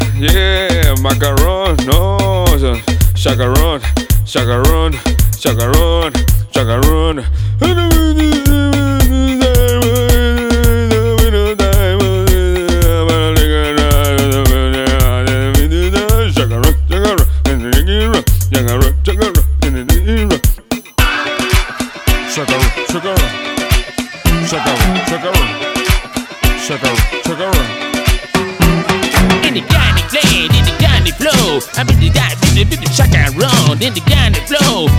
Dance Latin Urbano latino
Жанр: Танцевальные / Латино